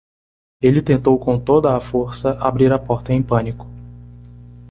Pronunciado como (IPA) /ˈto.dɐ/